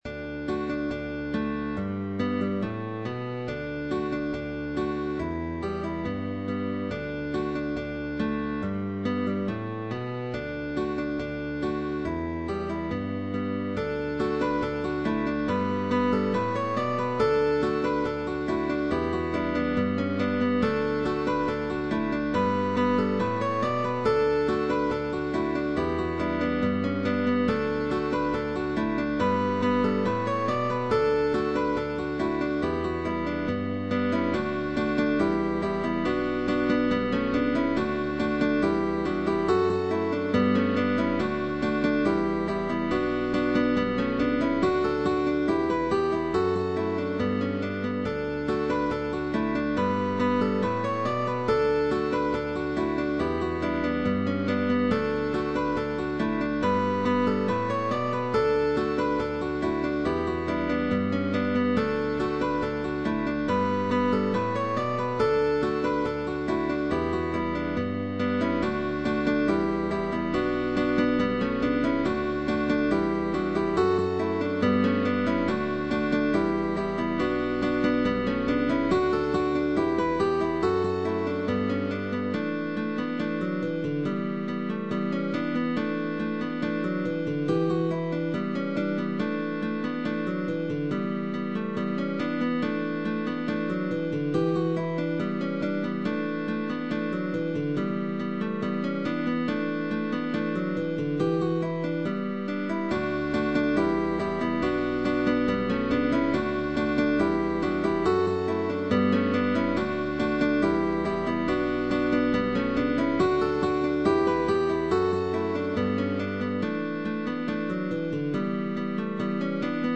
GUITAR QUARTET
is an Irish ballad
Change of position, index and middle “Apoyando”, chords,..